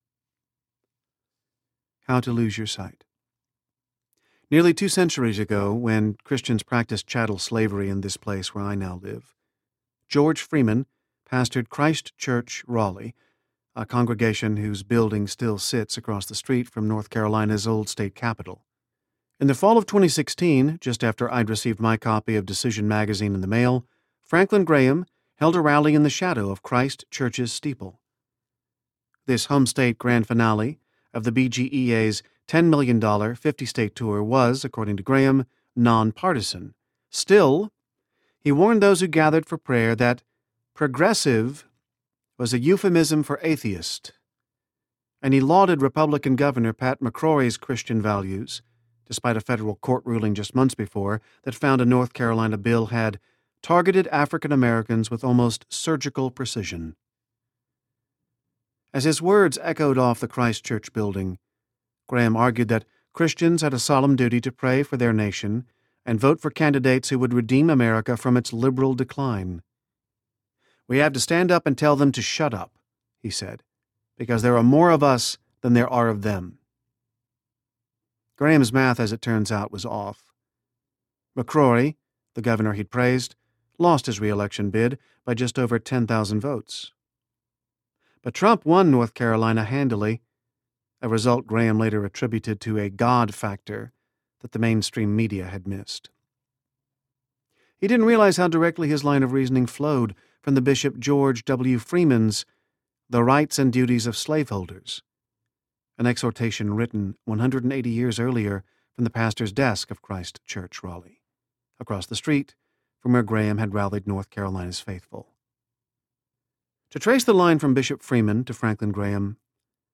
Reconstructing the Gospel Audiobook
Narrator